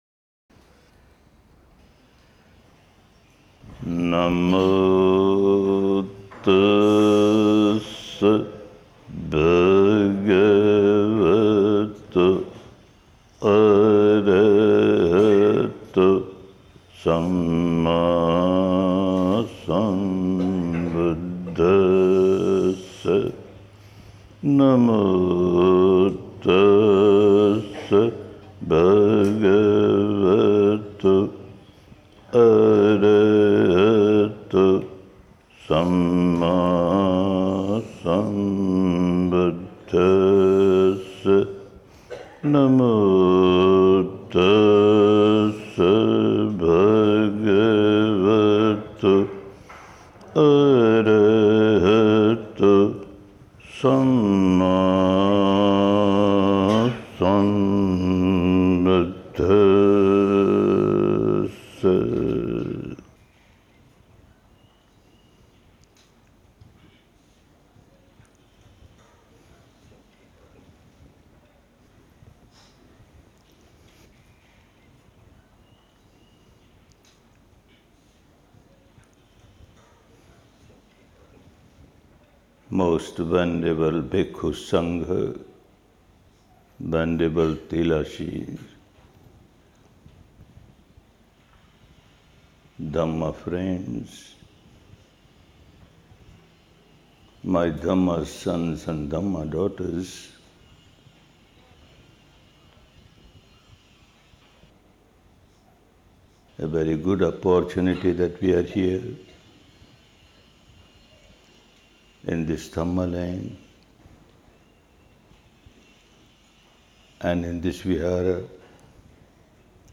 Sitagu University, Sagaing Hills, Mandalay, Myanmar • 3-Day Talk + Q&A - Day 01